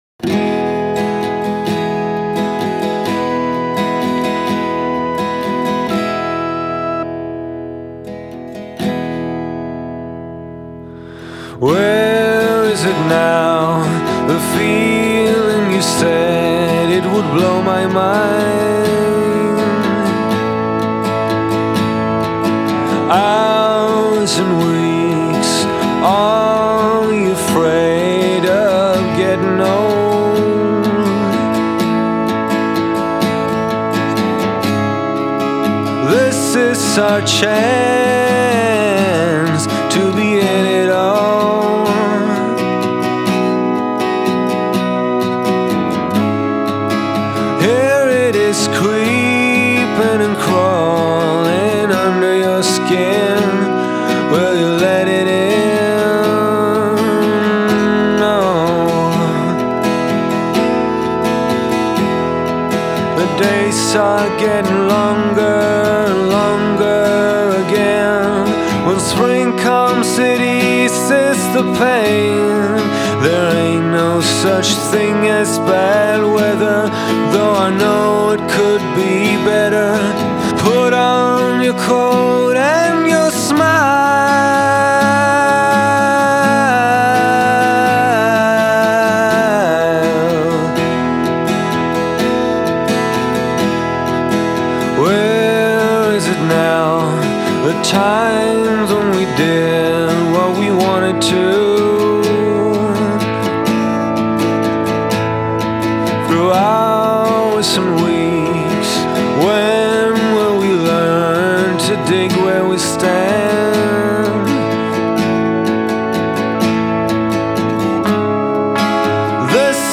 It's a bittersweet feeling, just like this song.